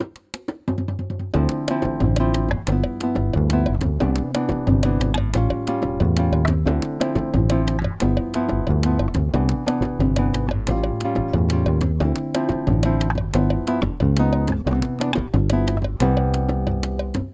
Apumateriaaliksi nauhoitin kymmenen toistoa siten, että muusikkoa säestävät harmoniset ja rytmiset instrumentit. Kaikki toistot äänitettiin kotistudiossani.